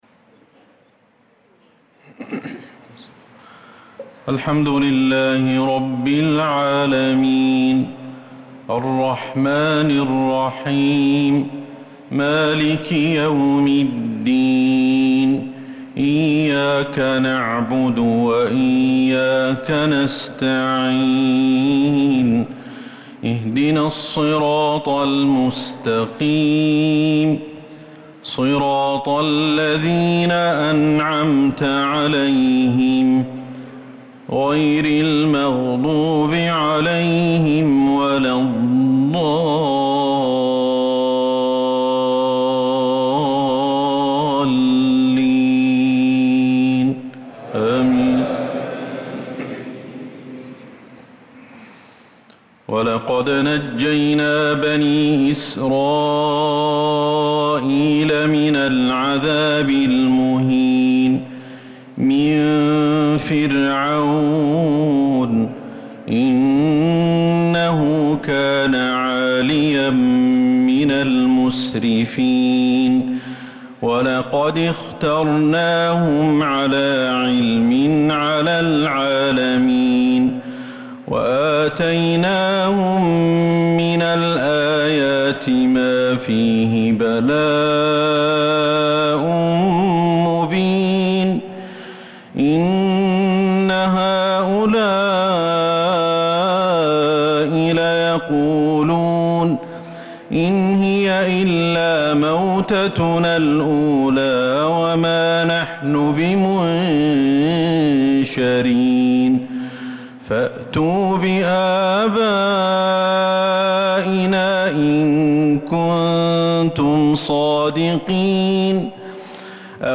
صلاة العشاء للقارئ أحمد الحذيفي 16 ذو الحجة 1445 هـ
تِلَاوَات الْحَرَمَيْن .